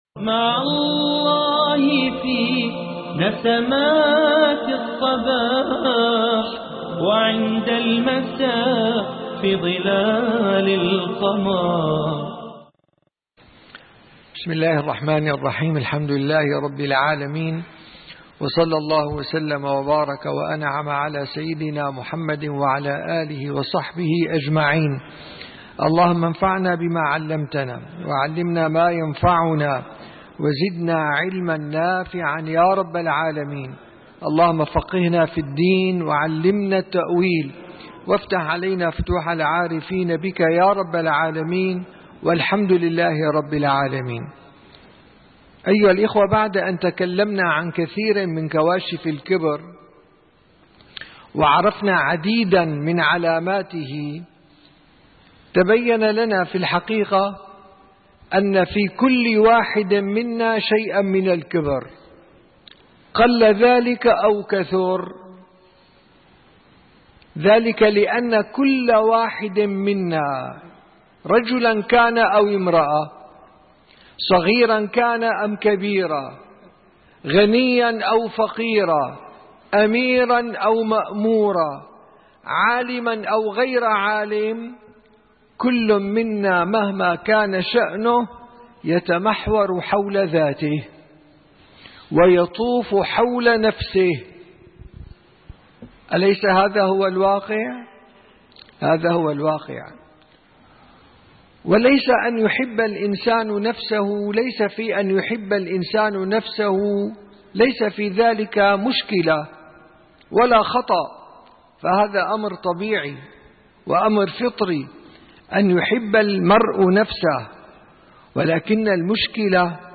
31 - درس جلسة الصفا: كيف نعالج الكبر وكيف نتخلص منه